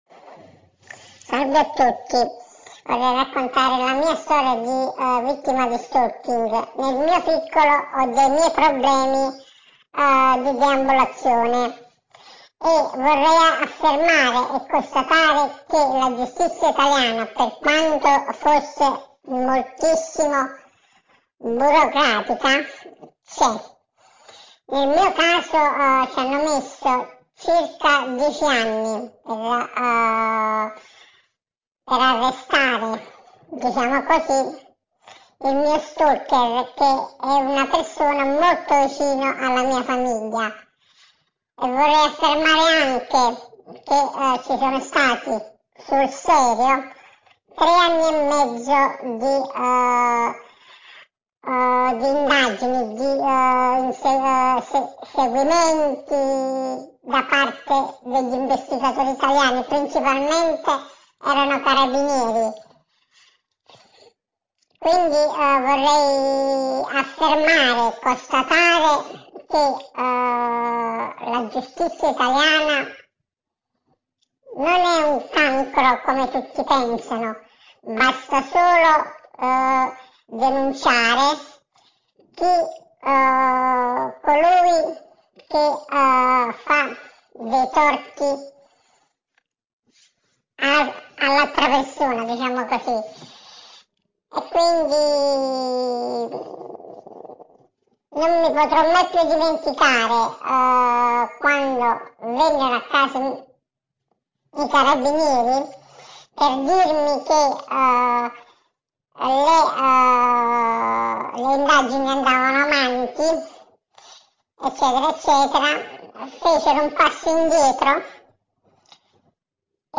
Audio con la testimonianza di una vittima di stalking
AUDIO- TESTIMONIANZA VITTIMA STALKING (la voce è stata modificata)